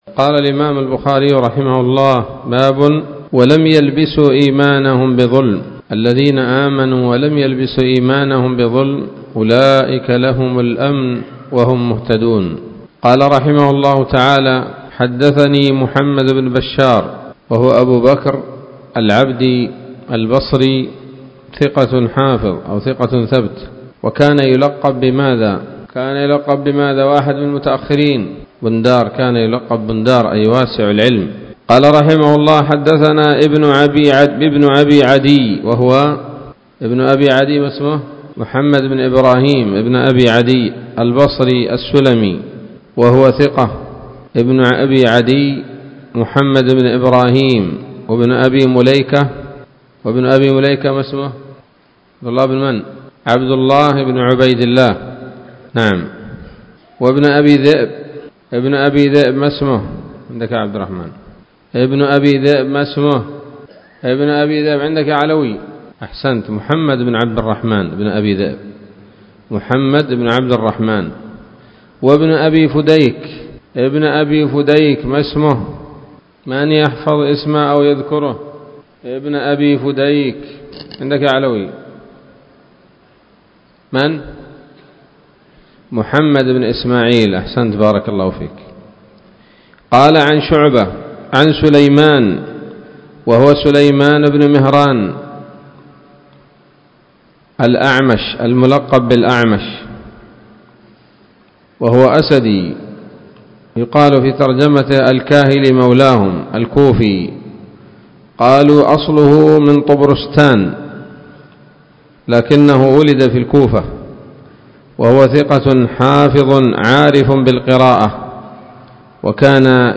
الدرس الأول بعد المائة من كتاب التفسير من صحيح الإمام البخاري